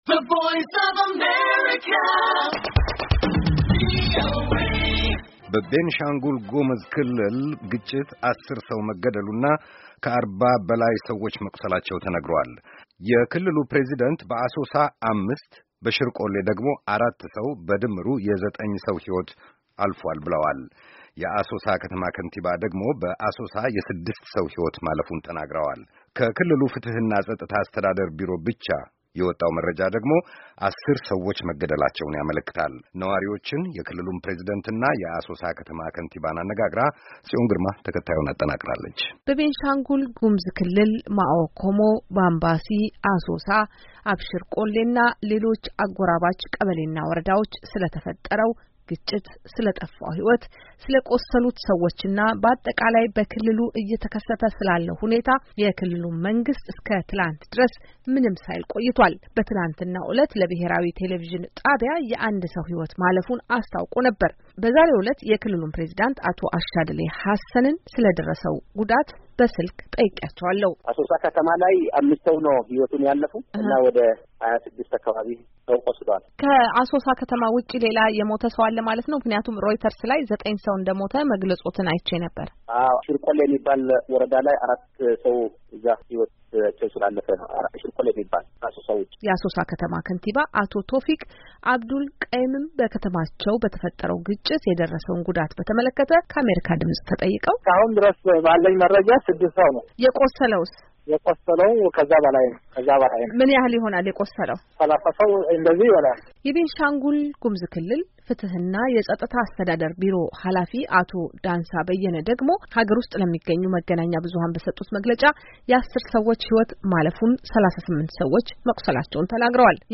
ነዋሪዎች፣ የክልሉን ፕሬዝዳንትና የአሶሳ ከተማ ከንቲባን አነጋግራ ተከታትዩን አጠናቅራለች።